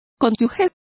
Complete with pronunciation of the translation of partner.